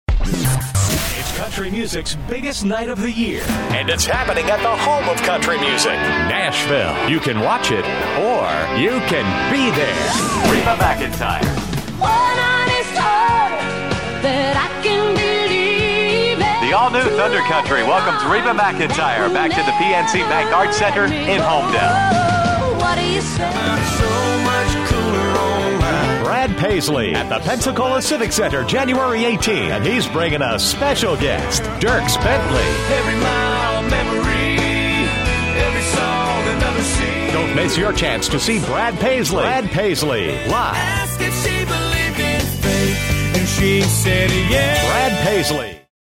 Male
The vibe is Warm, Friendly, Familiar, and Trustworthy, with just enough Edge to keep it interesting.
Music Promos
Country Compilation